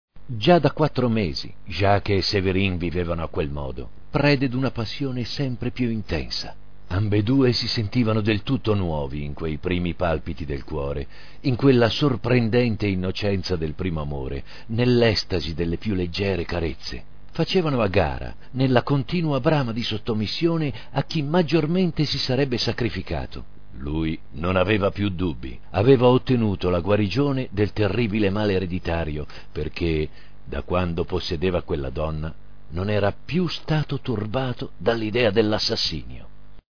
in un estratto da una lettura de "La bestia umana" di Emile Zola.